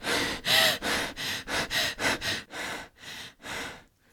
panic_w_1.ogg